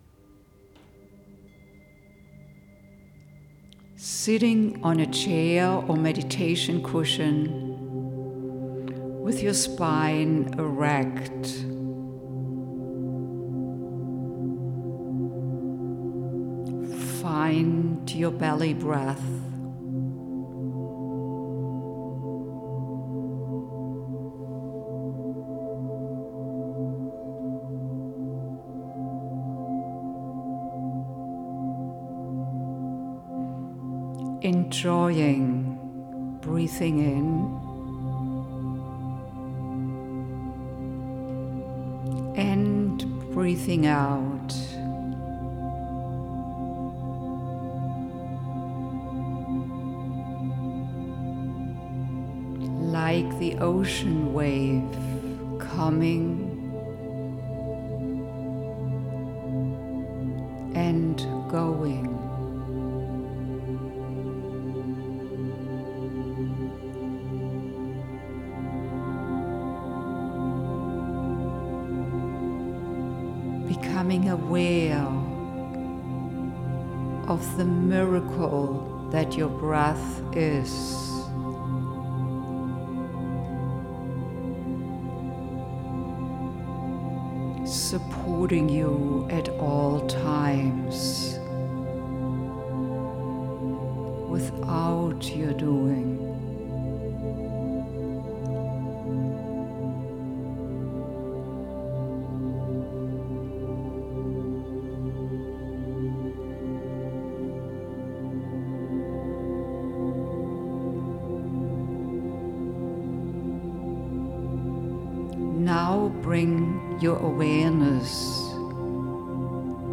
Gratitude Meditation